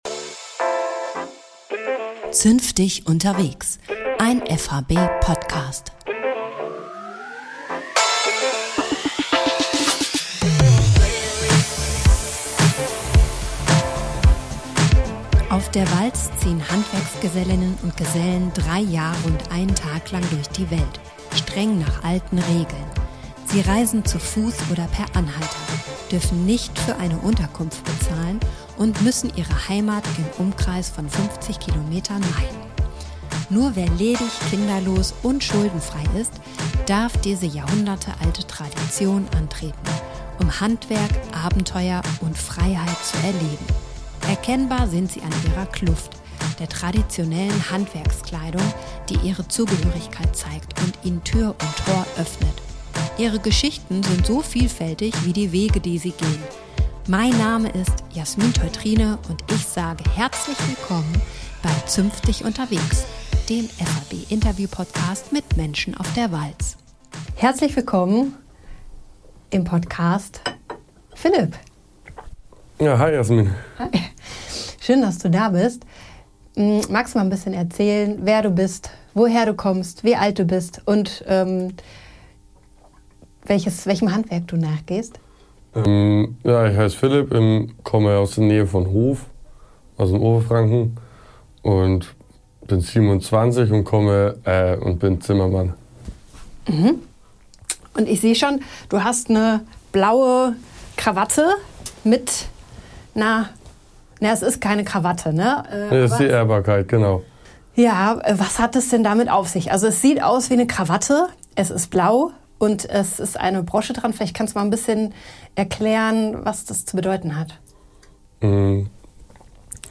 Improvisation, Handwerkskunst und Mut waren gefragt. Ein Gespräch über Freiheit, Abenteuer und die vielen Wege, die man als Geselle auf der Walz gehen kann.